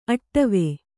♪ aṭṭave